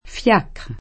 fL#kr] s. m.; pl. fiacres [id.] — adattam. tosc. fiacchere [fL#kkere]: saltai sopra un fiàcchere di passaggio [Salt#i Sopra un fL#kkere di paSS#JJo] (D’Annunzio); di qui il der. fiaccheraio